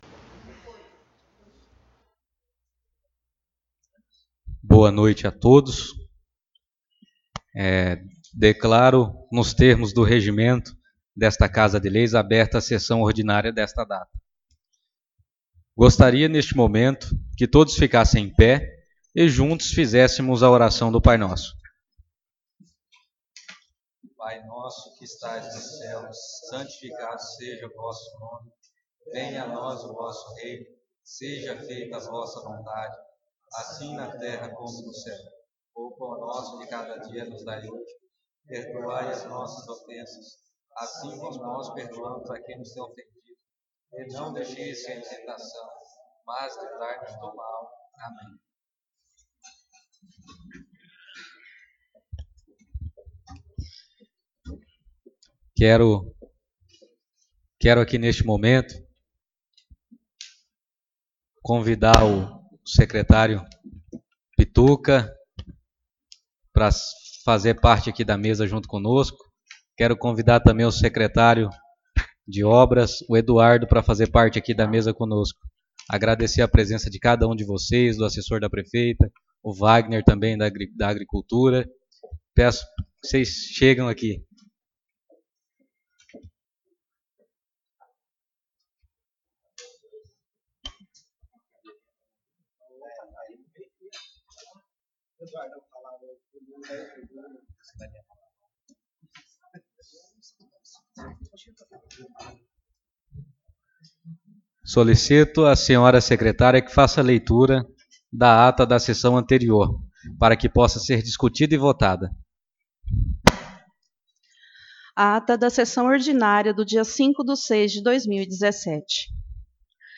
Sessão Ordinária 12/06/2017
Áudio das Sessões